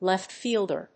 アクセントléft fíelder